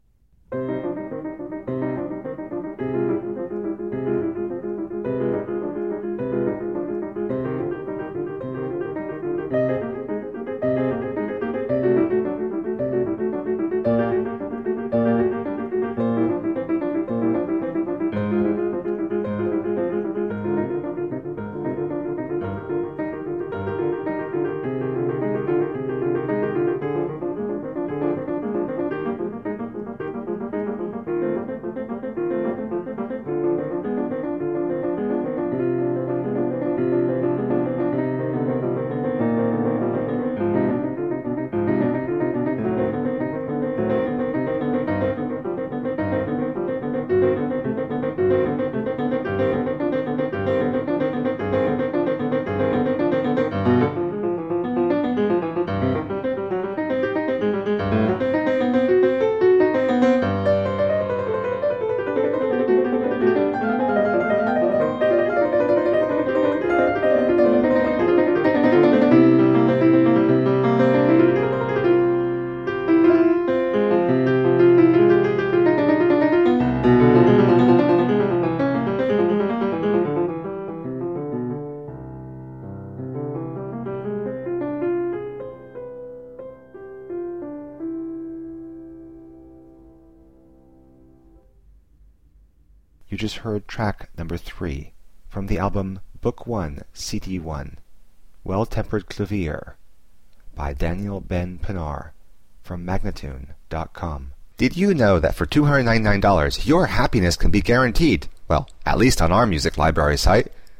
played with deep expressiveness and intelligence
solo piano music